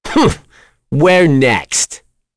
Mitra-Vox_Victory.wav